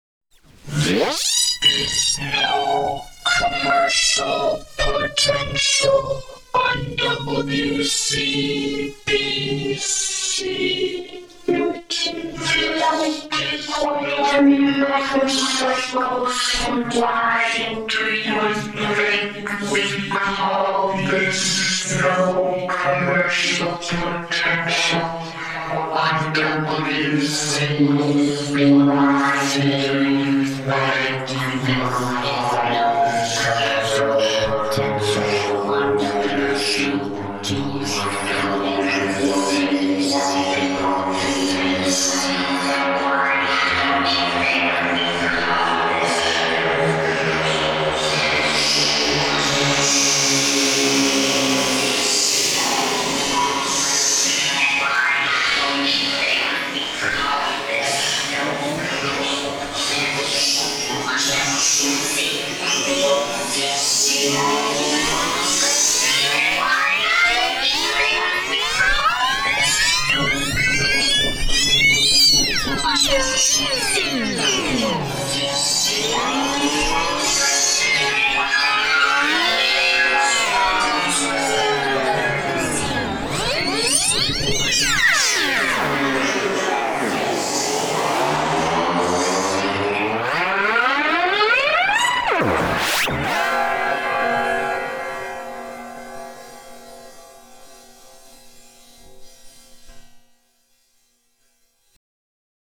NCP ID 1998 remix Legal ID